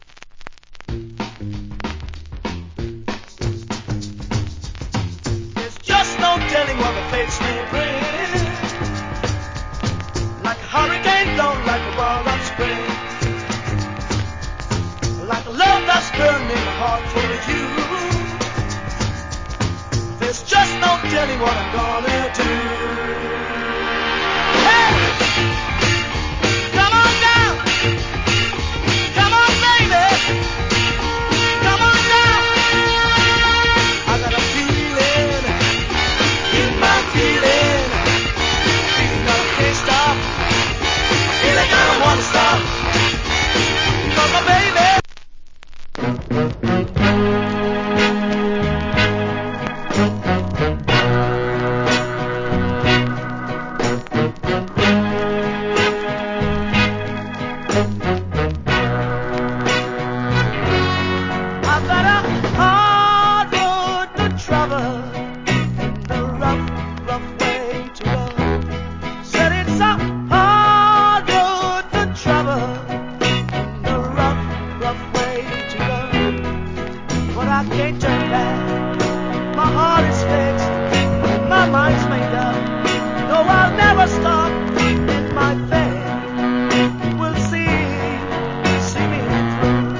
Nice Up Tempo Vocal.